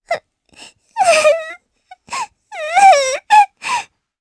Lilia-Vox_Sad_jp.wav